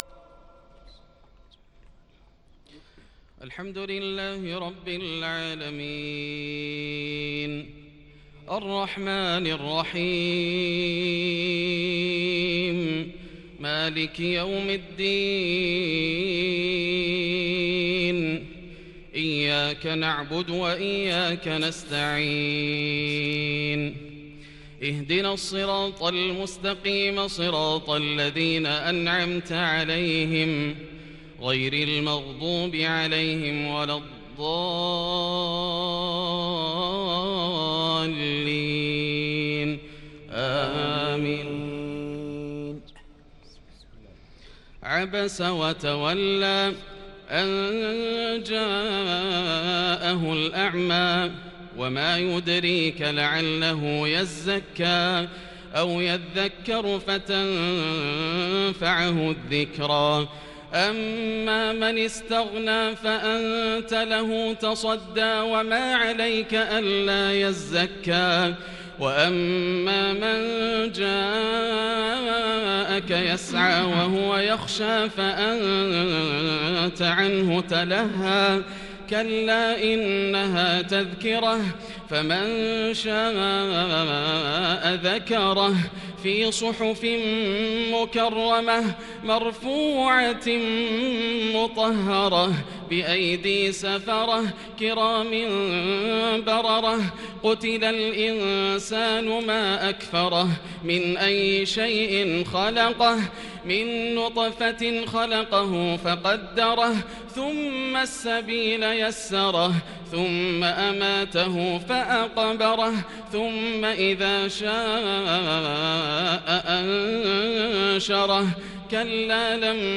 تهجد ليلة 29 رمضان 1441هـ من سورة عبس حتى سورة القدر | Tahajjud on the night of Ramadan 29, 1441 AH from Surat Abs to Surat Al-Qadr > تراويح الحرم المكي عام 1441 🕋 > التراويح - تلاوات الحرمين